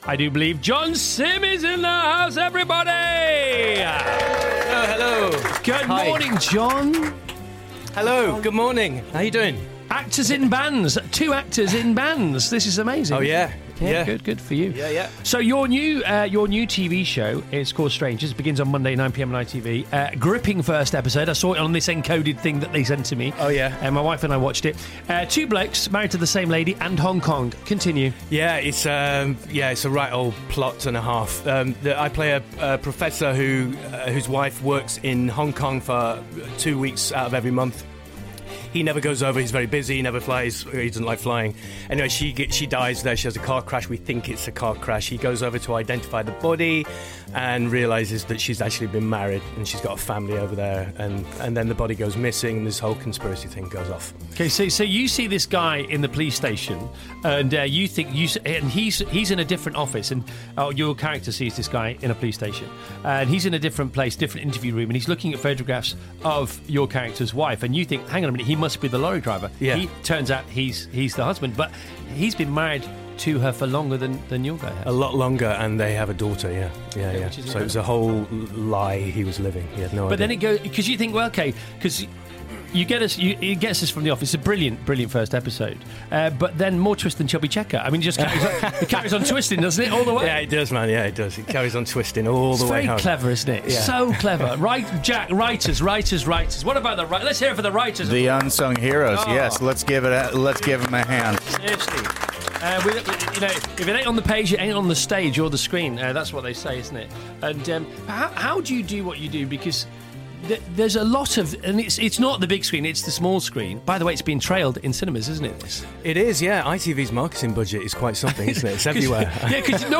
Radio Interview: John Simm shares secrets on his new thriller Strangers
Also in the studio with Chris Evans are Jamie Oliver, Jack Black and indie rock band Razorlight.